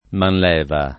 [ manl $ va ]